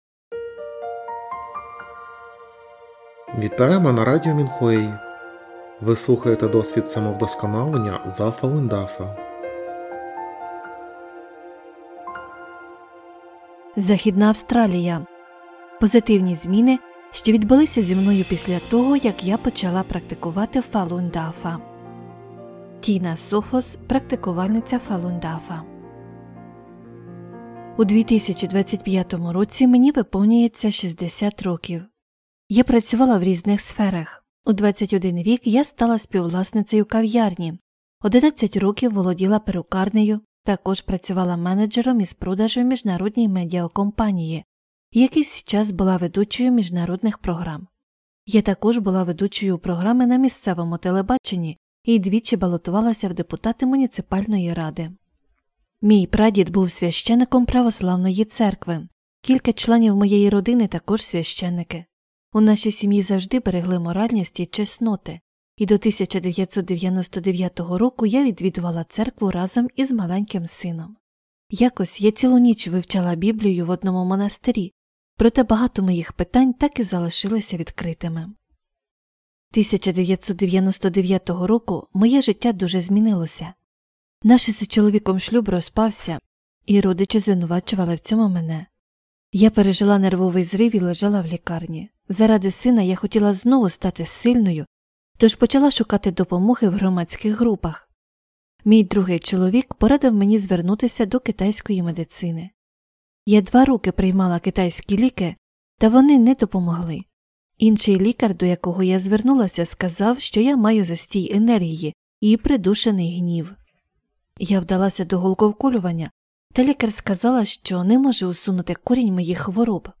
Музика з подкастів написана та виконана учнями Фалунь Дафа.